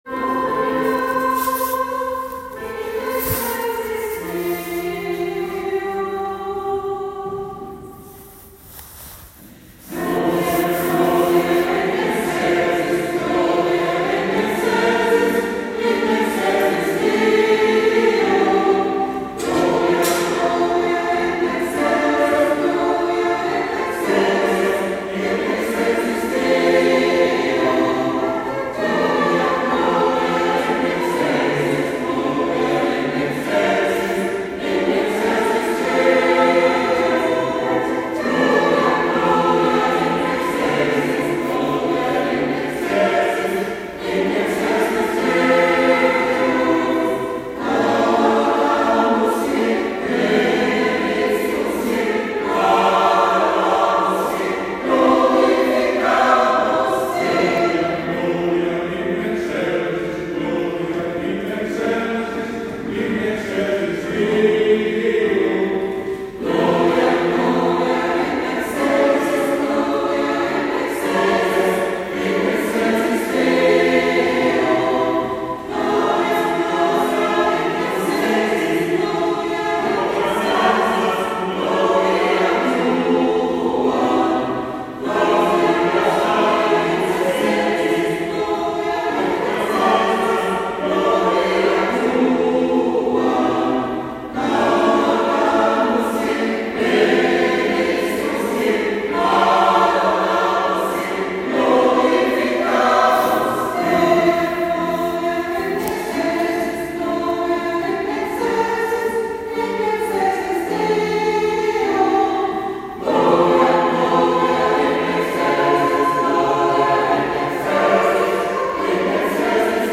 Ein Ostergruß vom Kirchenchor ….